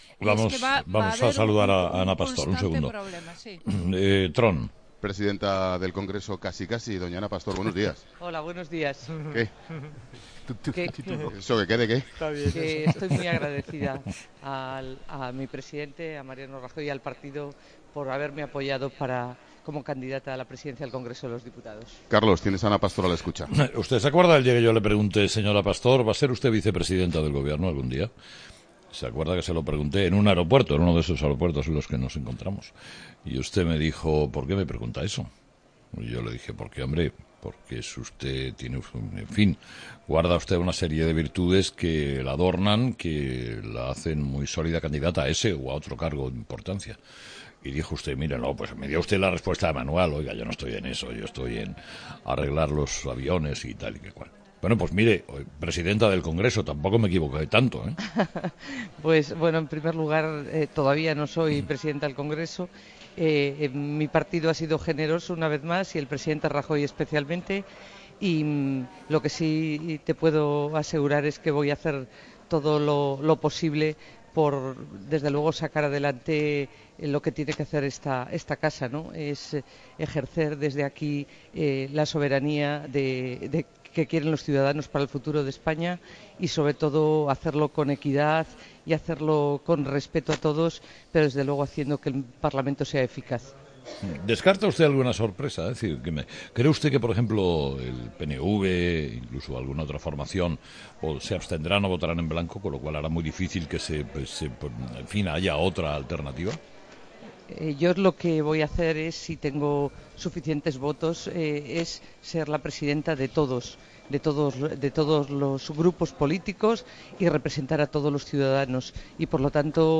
Entrevista a Ana Pastor COPE..
Entrevistado: "Ana Pastor"